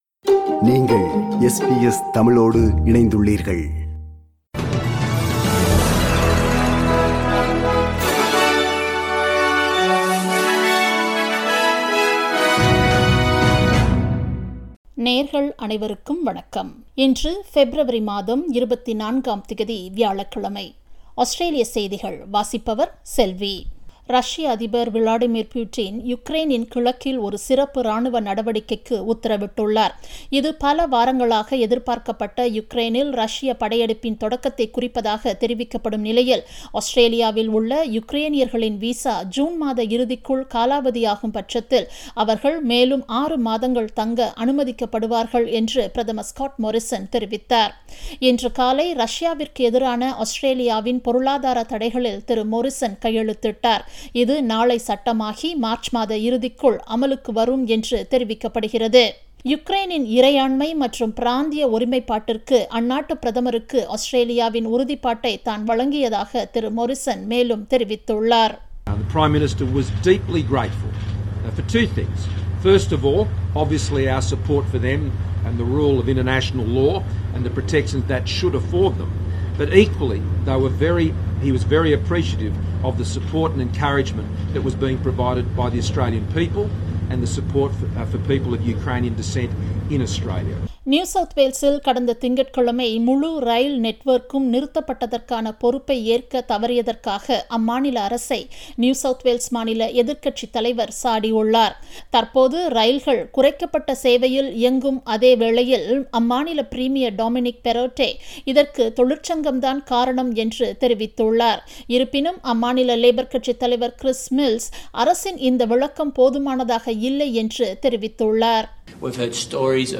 Australian news bulletin for Thursday 24 Febraury 2022.
australian_news_24_feb_-_thursday.mp3